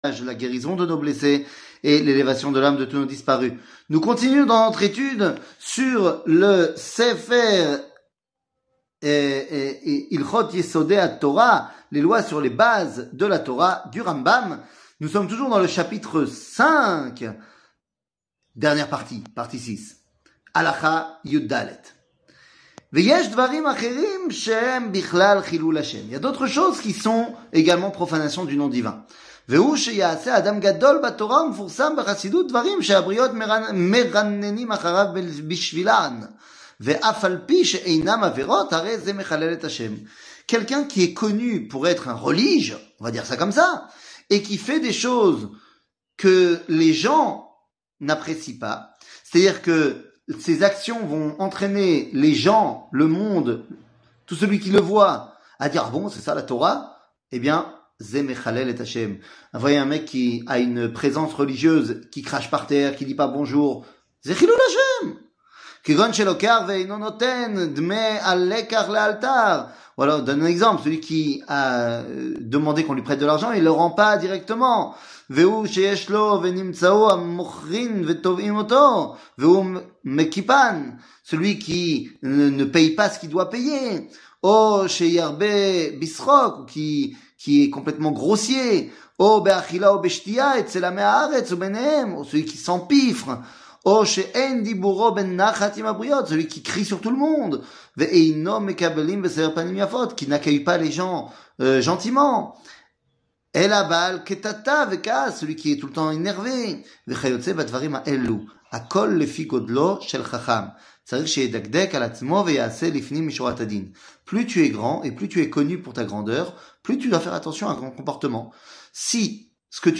שיעור מ 13 מרץ 2024